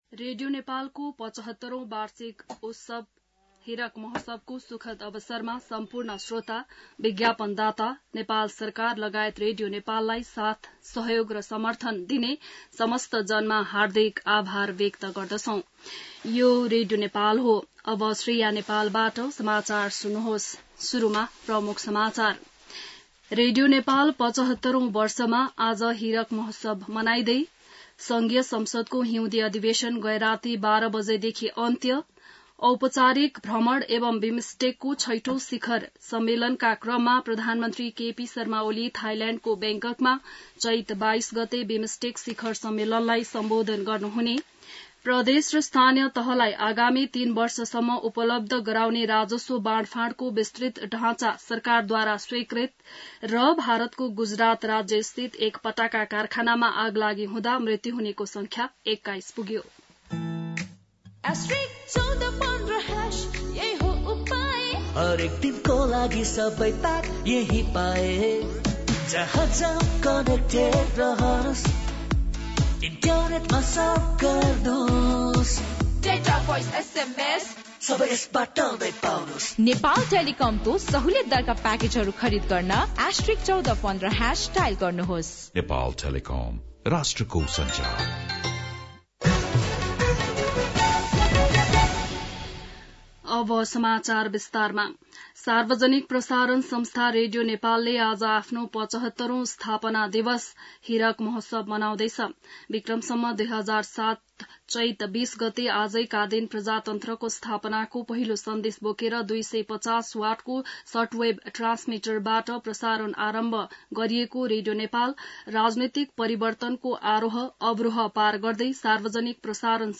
बिहान ७ बजेको नेपाली समाचार : २० चैत , २०८१